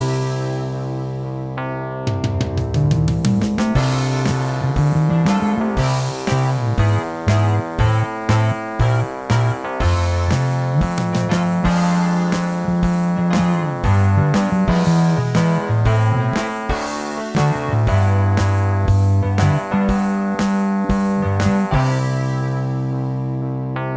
Minus Lead Guitar Rock 2:48 Buy £1.50